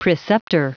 Prononciation du mot preceptor en anglais (fichier audio)
Prononciation du mot : preceptor